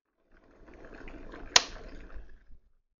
Wasserkocher
Dieser Sound erklingt, wenn der Wasserkocher bei seiner Maximaltemperatur abschaltet.
wasserkocher